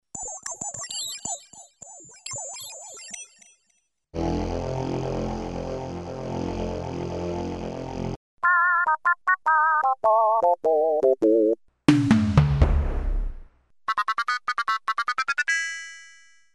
Kaway K1 was introduced by the end of the eighties (88 maybe?) and was a minor sensation with its sampled sound snippets and then fashionable digital sound.
Finally there's the option to amplitude modulate two sources by each other, which can create ring modulation-like effects, nasty and dirty sounds or just subtle movement